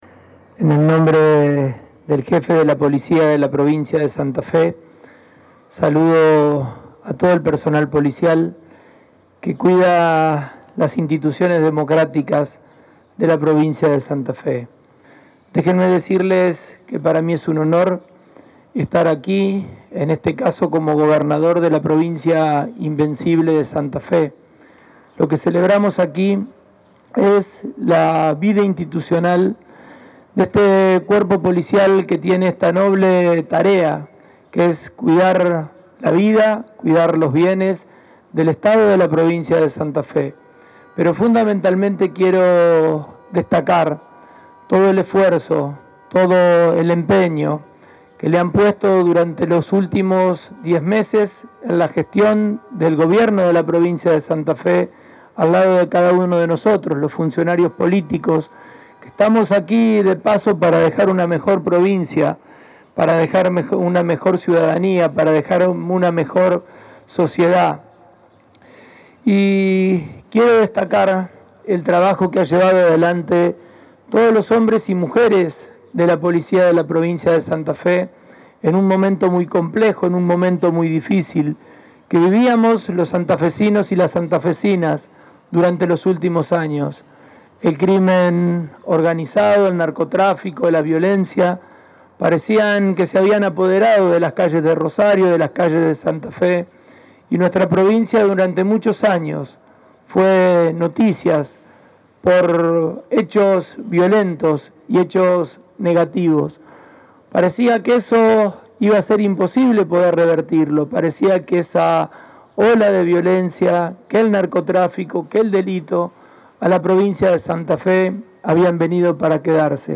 Fue este martes, en la Plaza 25 de Mayo.
Fragmentos del discurso del Gobernador